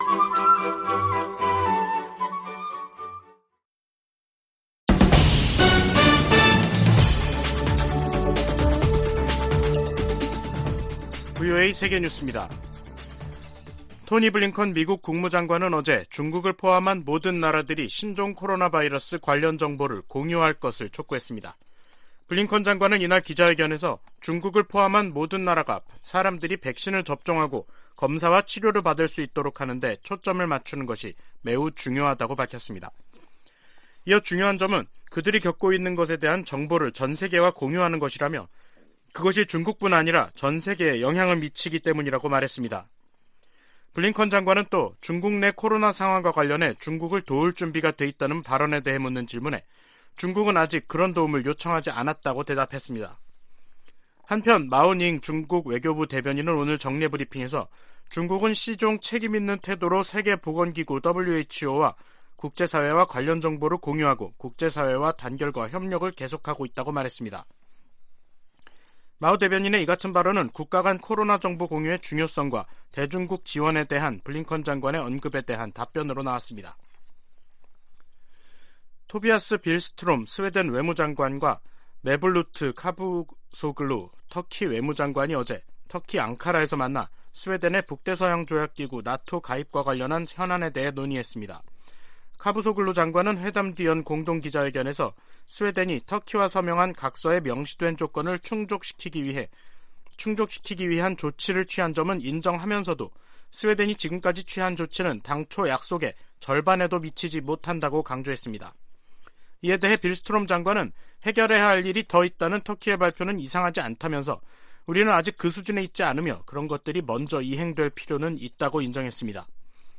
VOA 한국어 간판 뉴스 프로그램 '뉴스 투데이', 2022년 12월 23일 2부 방송입니다. 북한이 정찰위성 시험이라며 탄도미사일을 발사한 지 닷새만에 또 다시 탄도미사일을 발사했습니다. 미국 백악관은 러시아의 우크라이나 침공을 지원하는 현지 용병업체에 북한이 로켓과 미사일을 전달했다며, 북한-러시아 간 무기 거래 사실을 확인했습니다.